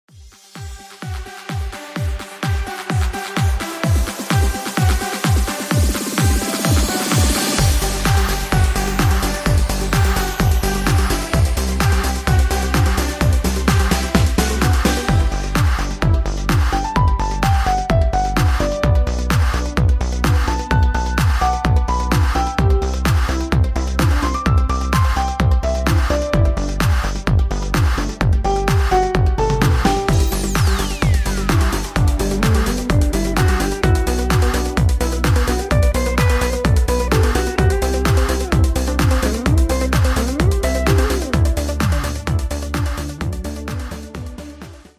Category: Patter